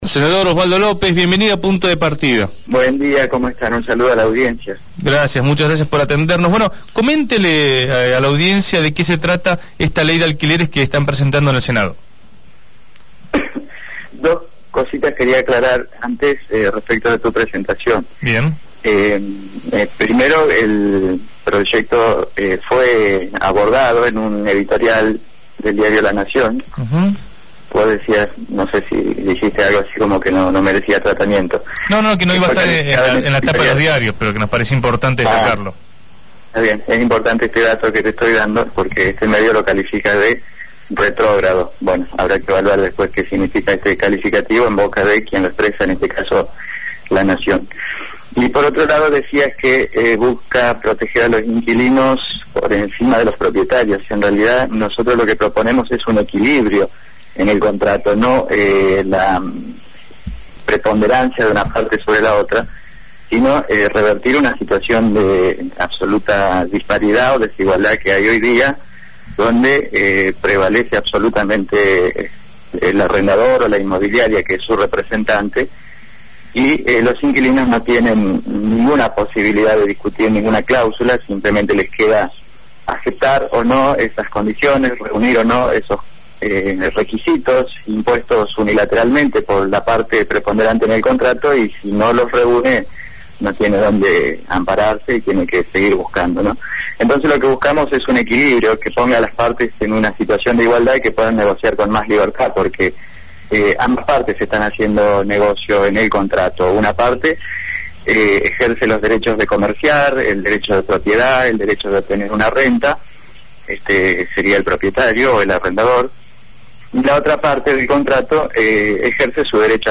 El senador nacional Osvaldo López habló en Punto de Partida.